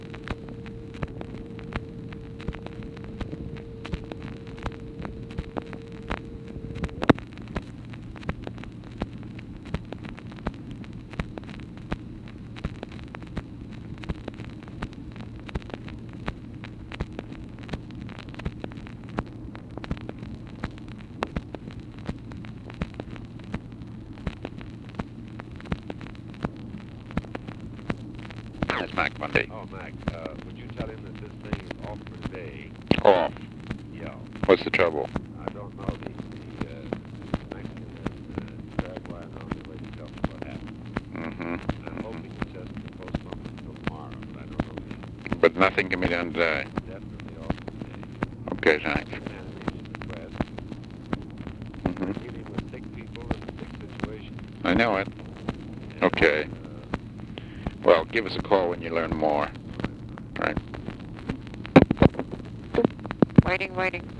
Conversation with THOMAS MANN?, March 12, 1964
Secret White House Tapes